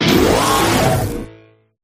toxtricity_ambient.ogg